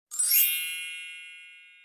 magic success.mp3